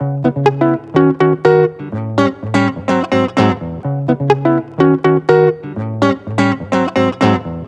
Electric Guitar 16.wav